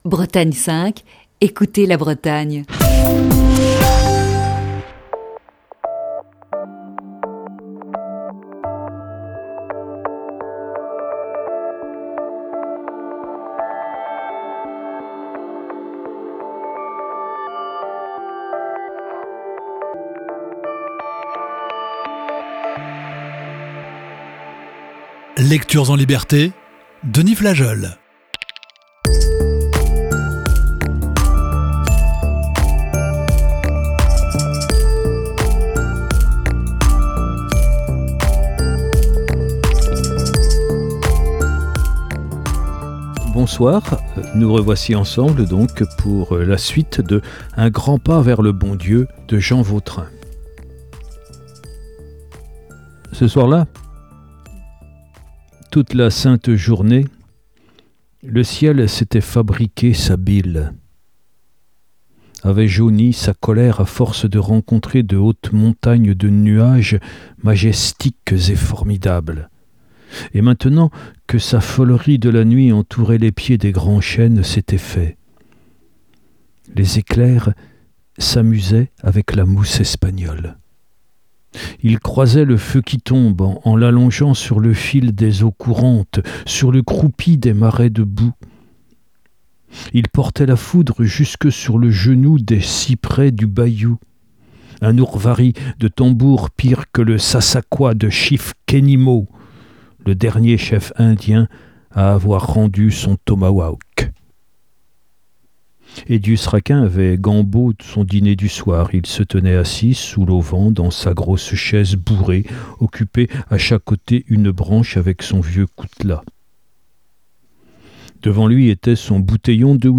la lecture de la deuxième partie de ce récit